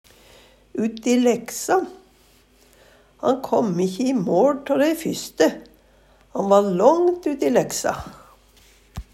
uti leksa - Numedalsmål (en-US)